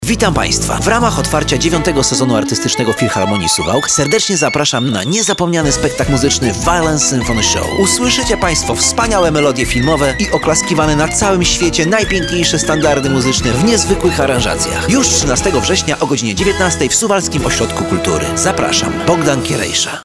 Violin_Show_reklama.mp3